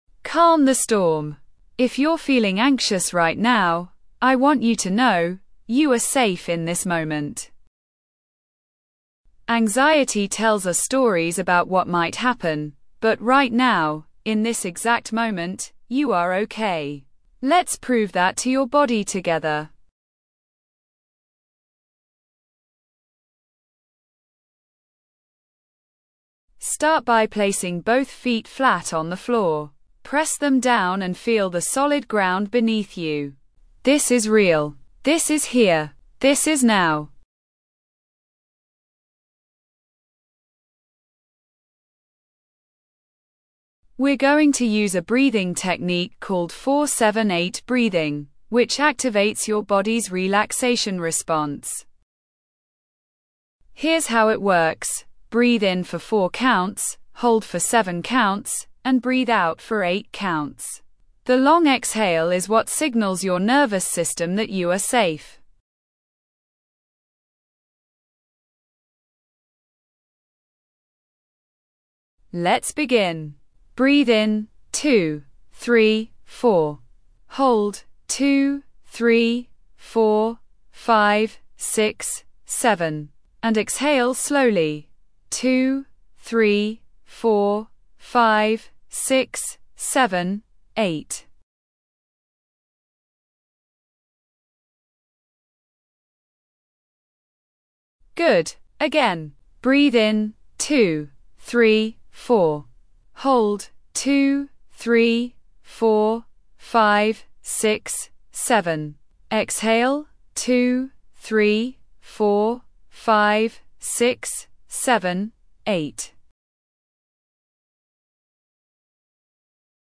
Calm the Storm: A Guided Meditation for Anxiety Relief